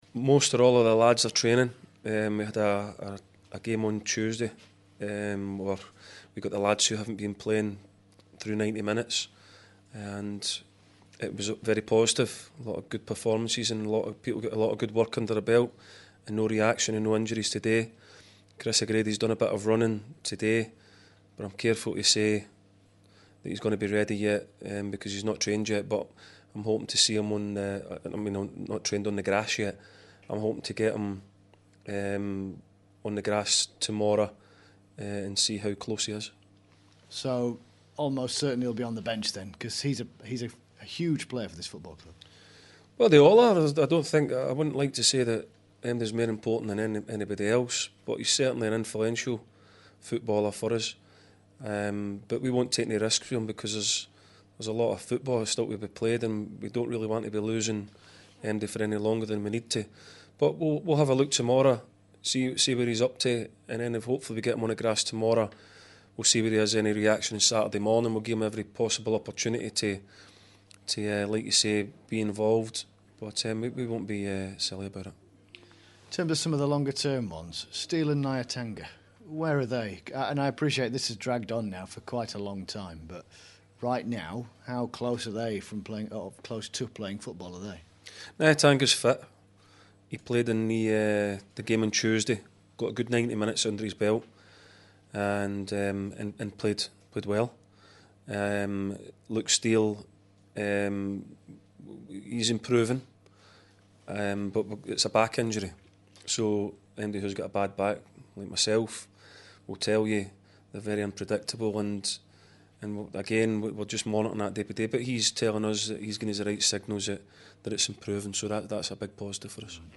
INTERVIEW: Barnsleyfc caretaker boss Micky Mellon